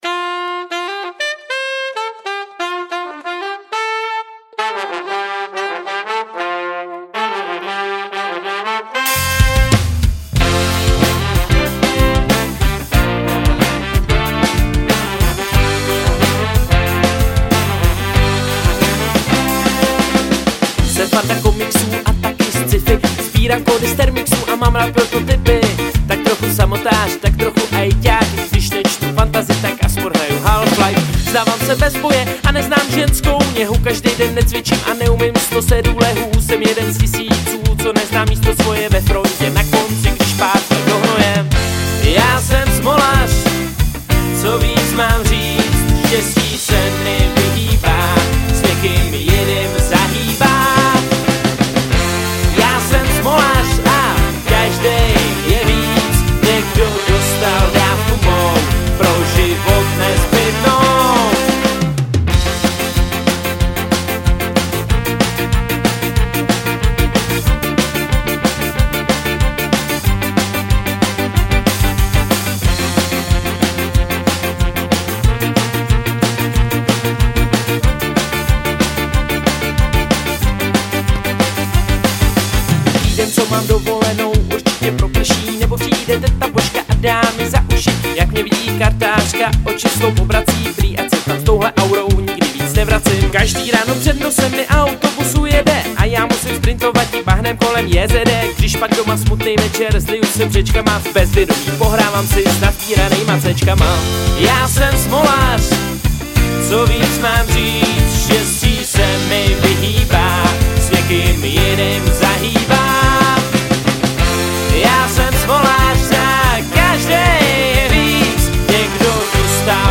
Žánr: Ska/Funk/Reggae